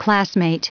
Prononciation du mot classmate en anglais (fichier audio)
Prononciation du mot : classmate